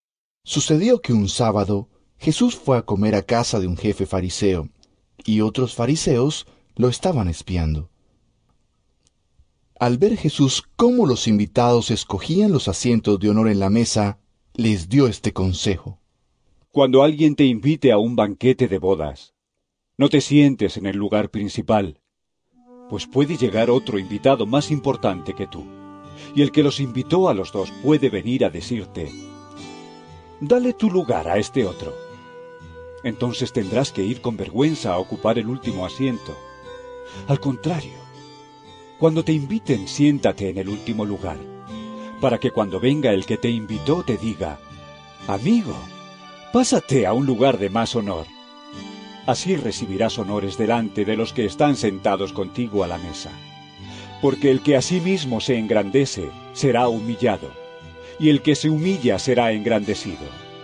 Lc 14 1.7-11 EVANGELIO EN AUDIO